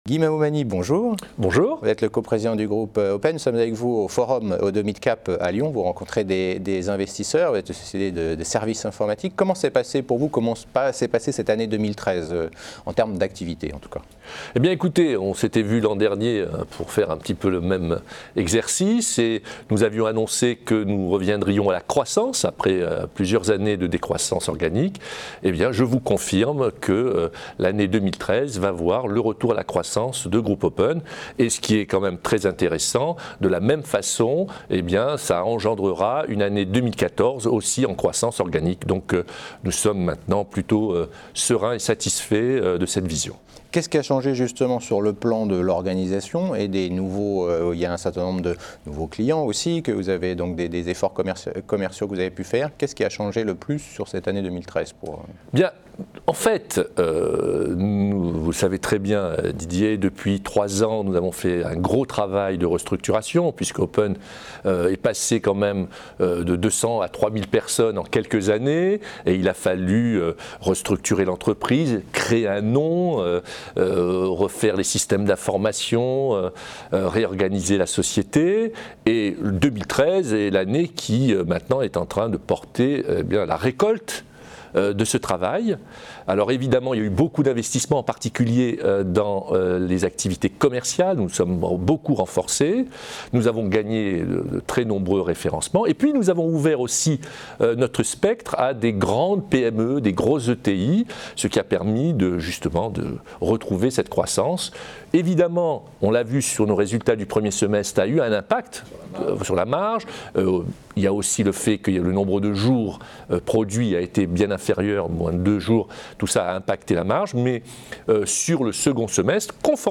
Oddo Midcap Forum 2014 : Stratégie et perspectives de Groupe Open